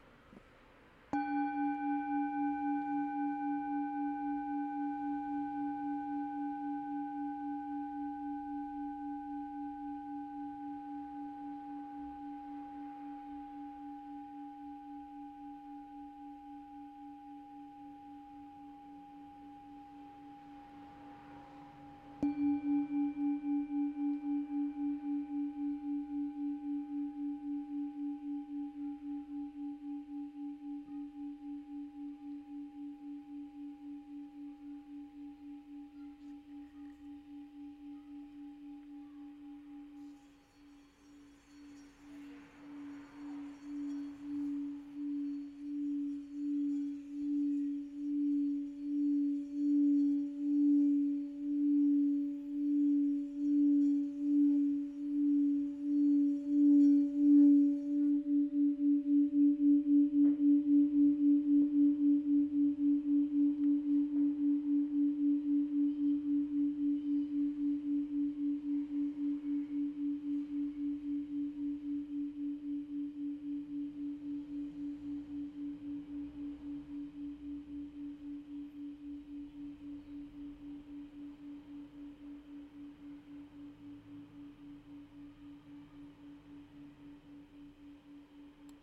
Campana Tibetana Nota RE(D) 4 292 HZ -R121/C
Nota Armonica   SOL(G) d5 821 HZ
Nota di fondo  RE(D) 4 292 HZ
Campana Tibetana Antica 100-300 anni, prodotto artigianale  di tradizione tibetana, in lega dei 7 metalli